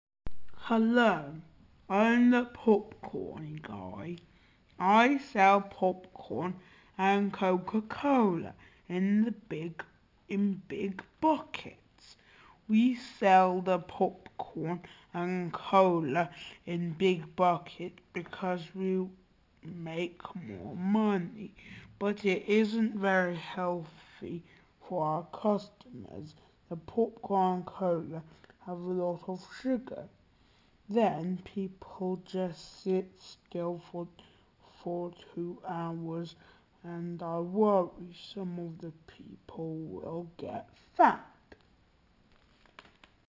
Listen to the popcorn seller:
popcorn-guy.mp3